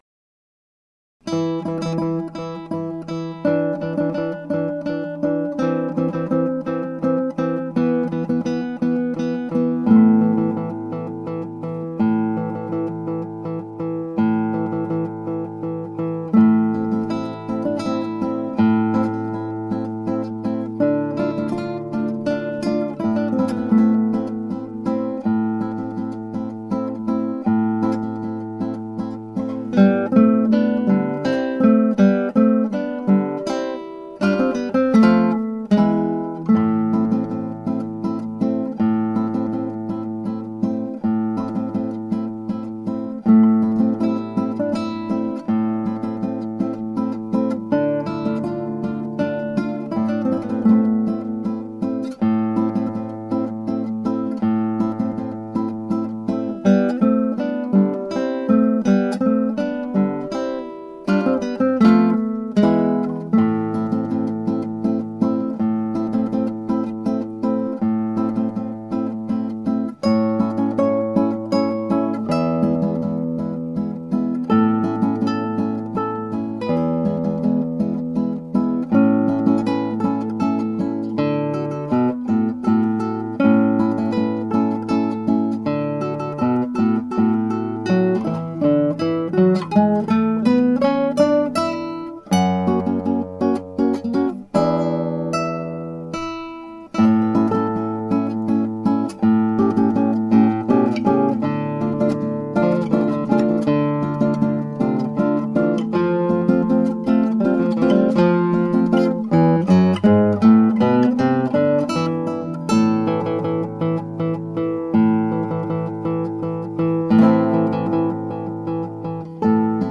(アマチュアのクラシックギター演奏です [Guitar amatuer play] )
再録しました。
初めての１人二重奏をやってみました。
2ndを最初に録音し、それをヘッドフォンで聴きながら1stを別のチャンネルに録音するやり方です。
なので、1stの弾けていなく無音のところがあります。